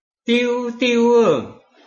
拼音查詢：【饒平腔】diu ~請點選不同聲調拼音聽聽看!(例字漢字部分屬參考性質)